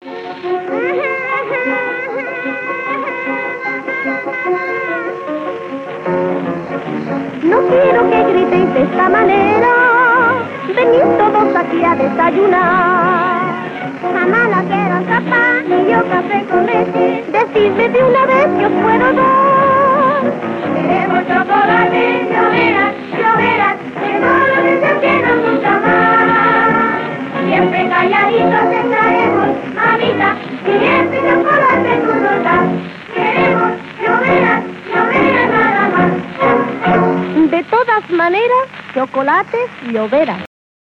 Anunci publicitari de Chocolates Lloveras